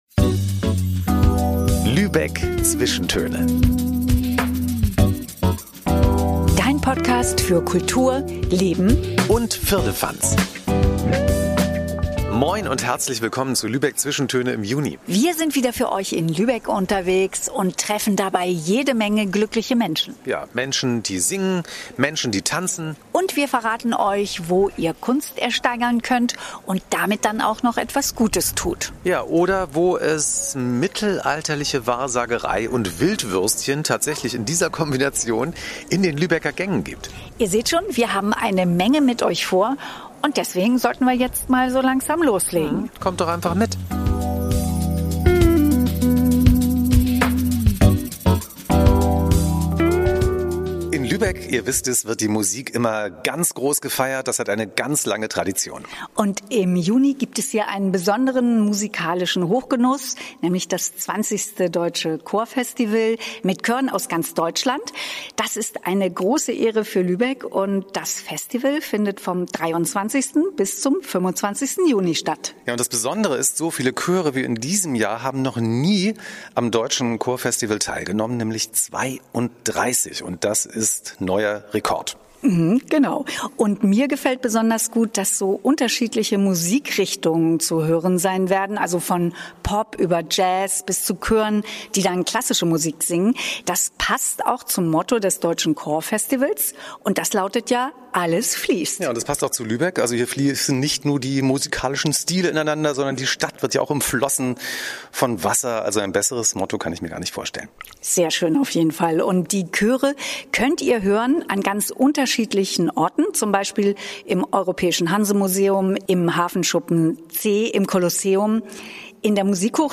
Diesen Monat wird gesungen und getanzt. Lübeck empfängt als Host Town das schwedische Team der Special Olympics. Und wir dürfen schon einmal bei den Proben für die große Tanz-Show vor dem Holstentor zusehen.